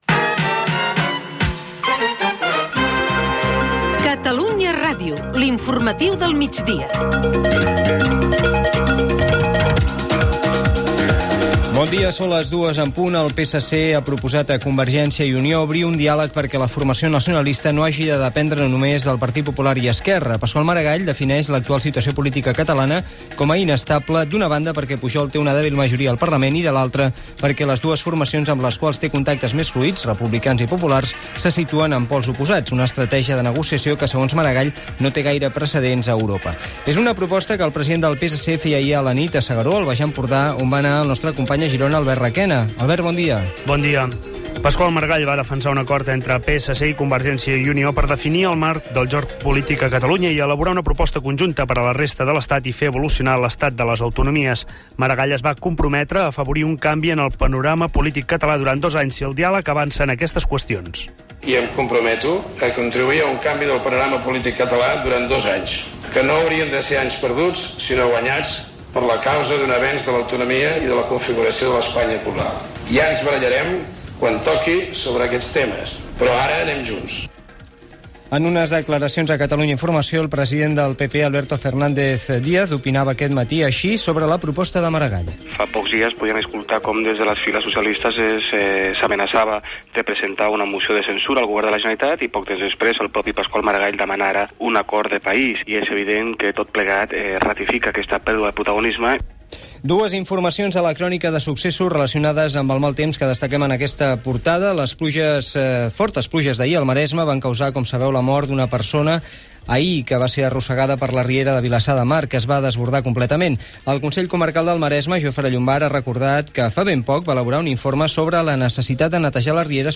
Careta del programa, proposta del Partit Socialista de Catalunya a Convergència i Unió, fortes pluges al Maresme, incendi a Mallorca, desplegament de cascs blaus de l'ONU, esports, el temps, estat del trànsit, indicatiu del programa, proposta del PSC a CiU amb declaracions de Pasqual Maragall i Alberto Fernández Díaz Gènere radiofònic Informatiu